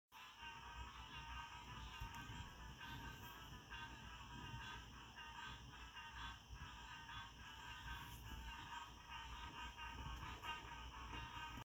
SoundsOfCoxFerry.mp3